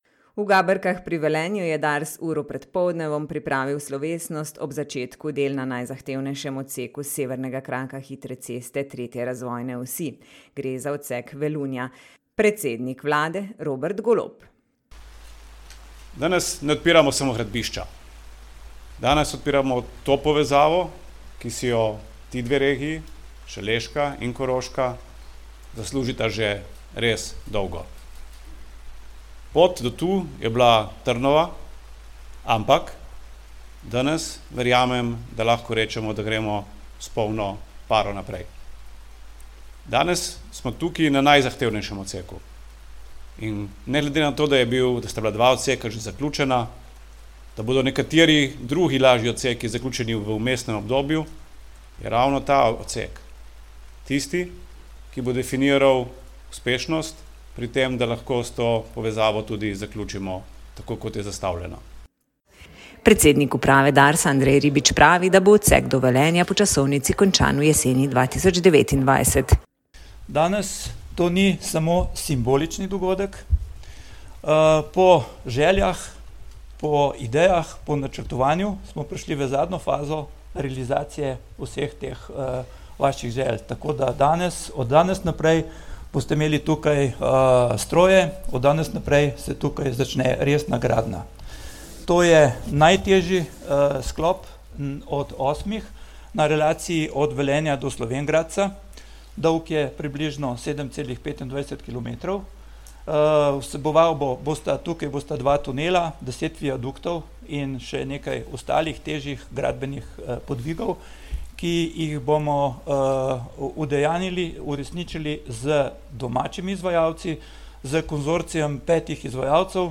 Predsednik vlade dr. Robert Golob se je danes udeležil slovesnosti ob začetku gradnje odseka Velunja na severnem delu 3. razvojne osi. Odsek predstavlja gradbeno in finančno najbolj zahteven del prihodnje hitre ceste proti Koroški.
Premier dr. Golob je zbrane tudi nagovoril.